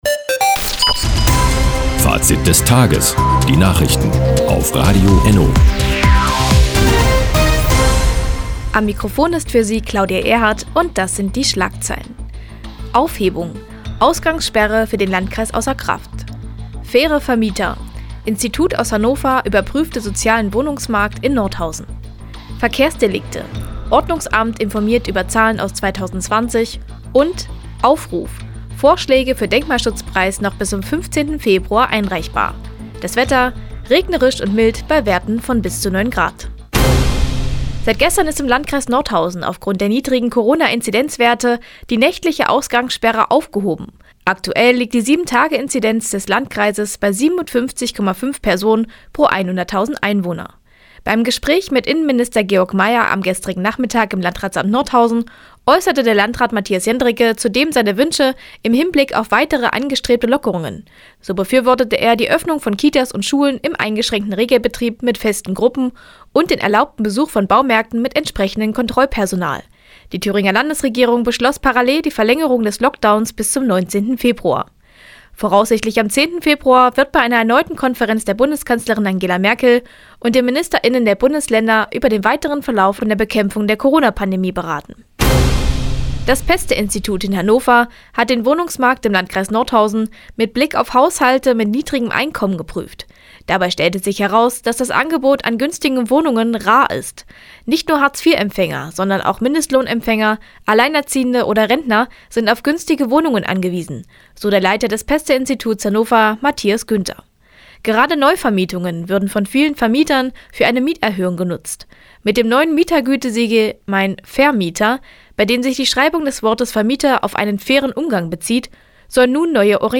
Die tägliche Nachrichtensendung ist jetzt hier zu hören.